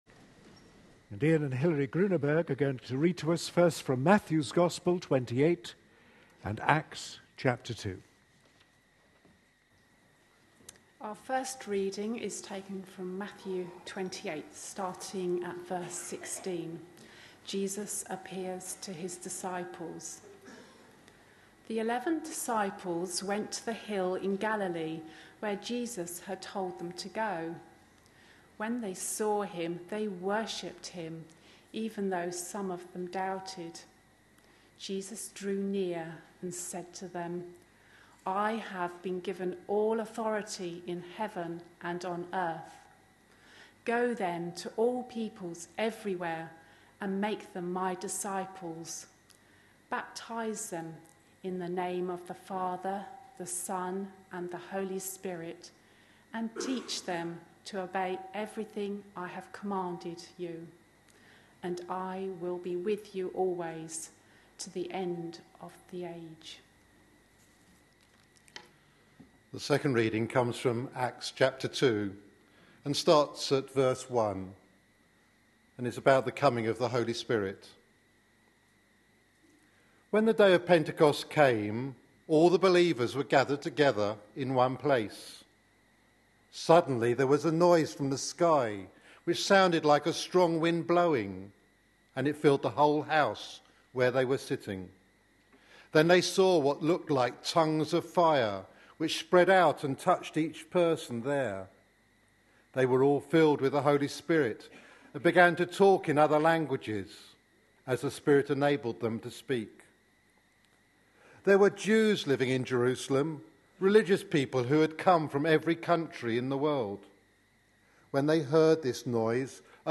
A sermon preached on 19th May, 2013, as part of our 108th Church Anniversary Services series.